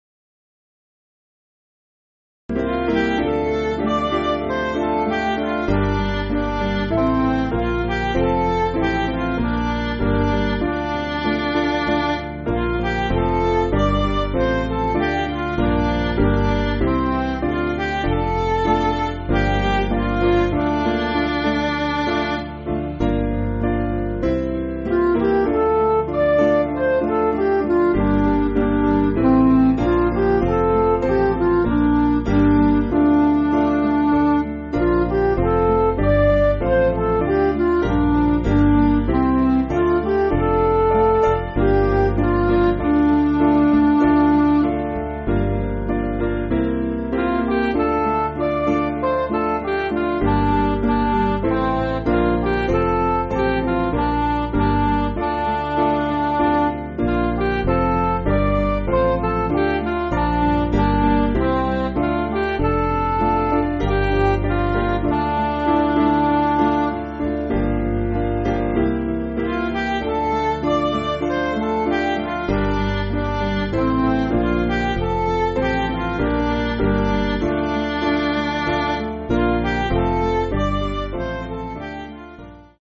Piano & Instrumental
(CM)   6/Dm